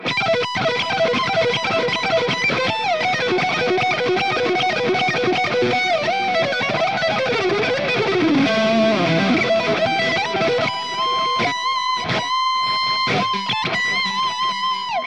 It's fully loaded for any Hi-Gain application. 100 Watts of sofisticated Rock and Metal tones, It's all about gain!
Lead
RAW AUDIO CLIPS ONLY, NO POST-PROCESSING EFFECTS